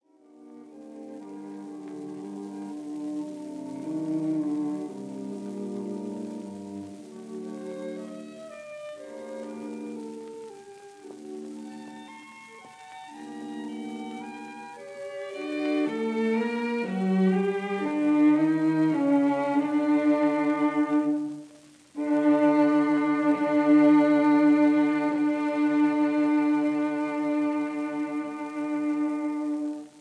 violins
viola
cello
in G minor, ending in G major — Lento